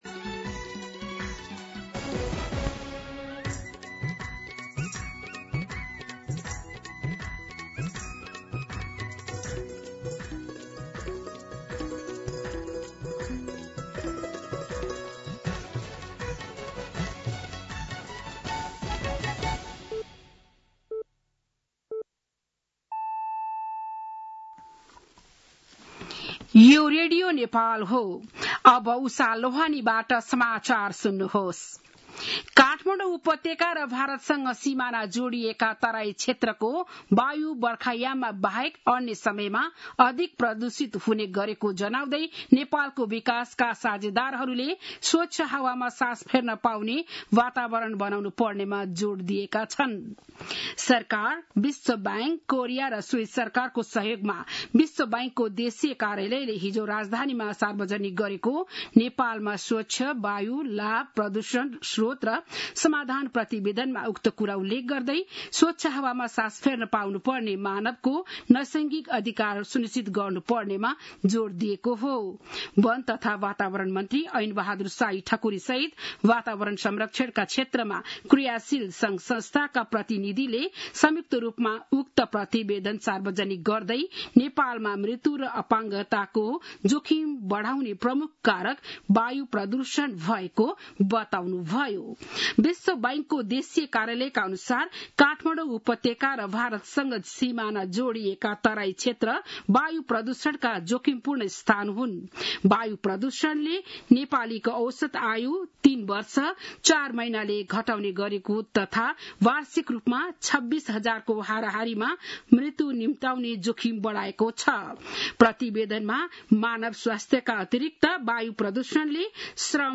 बिहान ११ बजेको नेपाली समाचार : ४ असार , २०८२